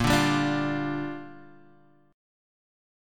A#6 chord